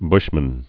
(bshmən)